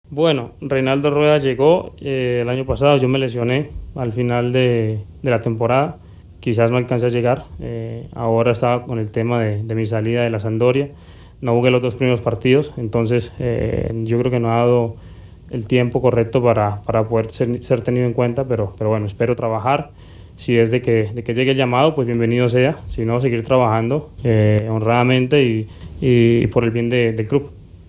De no ser así, seguiré trabajando honradamente y por el bien del club”, dijo Murillo en la rueda de prensa previa al partido de este fin de semana, ante Real Madrid.